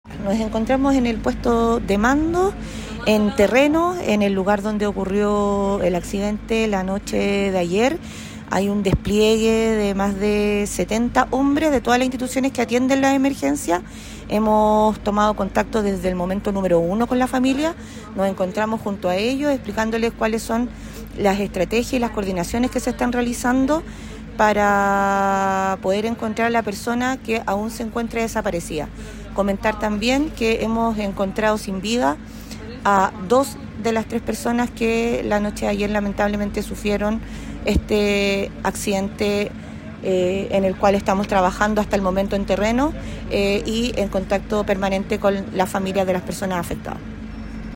Así fue el reporte de la Delegada Presidencial de Colchagua Marta Pizarro en horas de la mañana de este lunes: